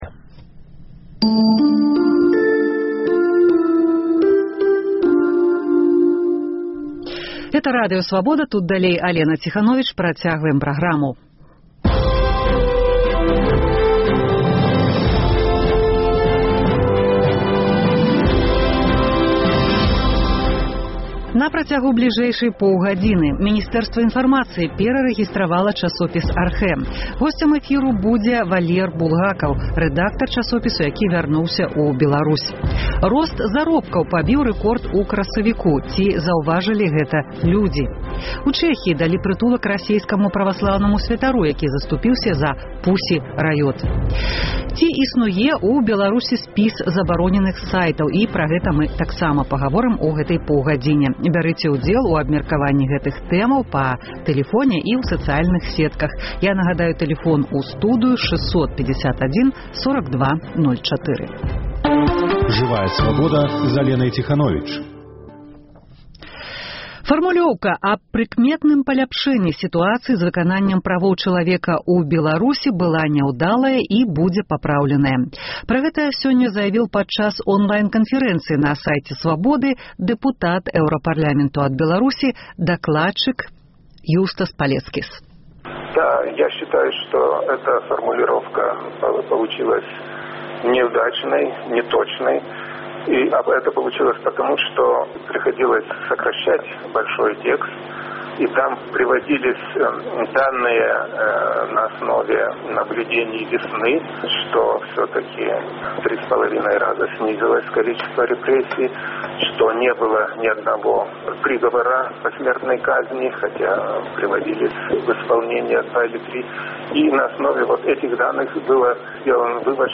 Ці існуе ў Беларусі сьпіс забароненых сайтаў? Бярыце ўдзел у абмеркаваньні гэтых тэмаў па тэлефоне і ў сацыяльных сетках.